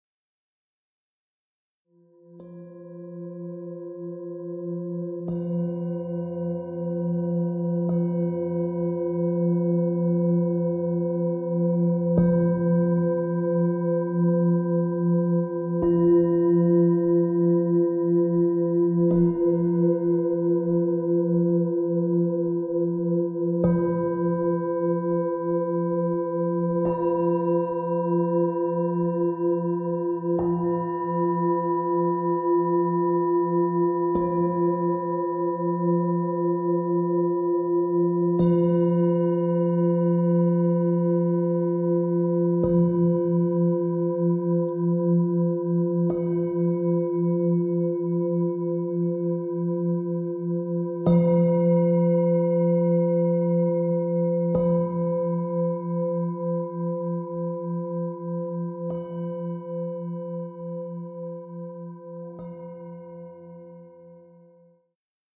Musik für Meditation mit planetarischen Tönen
eingespielt mit PlanetenKlangschalen und ChakraKlangschalen.
Musik mit Klangschalen und Planetentönen 7.